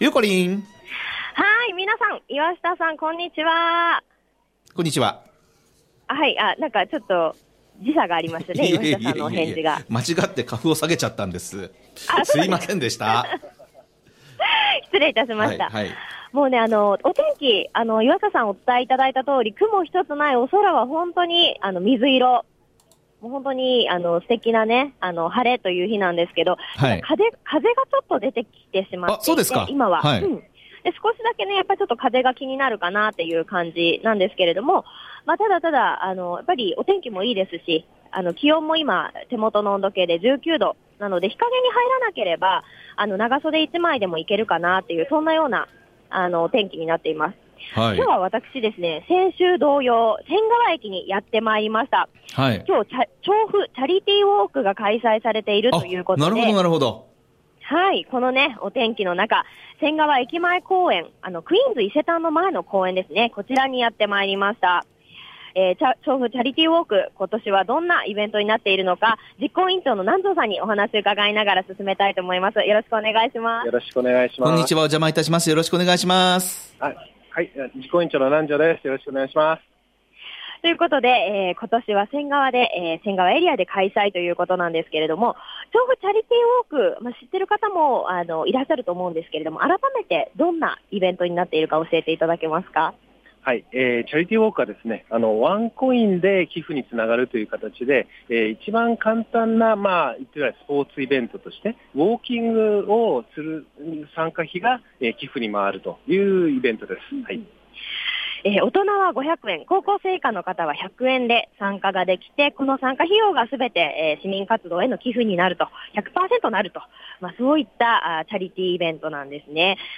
街角レポート